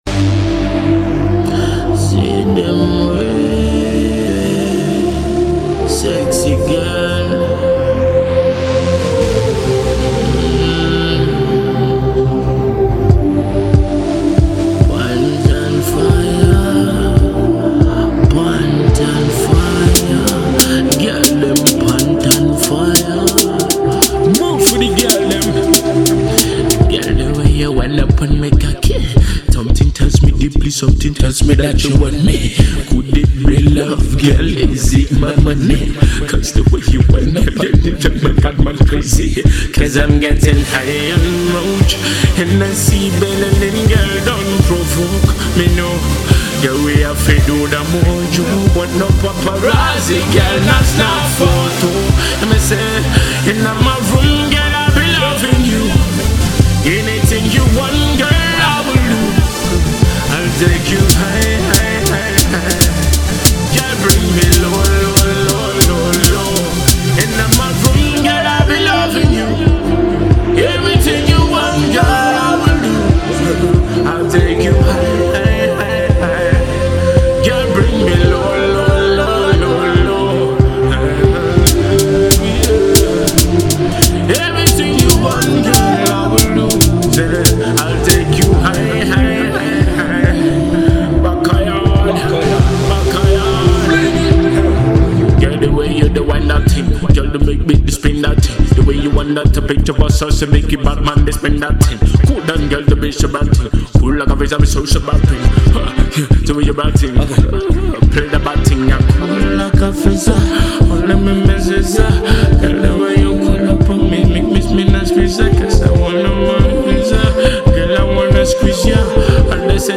a Patois-infused Dancehall-meets-Afro Pop experiment.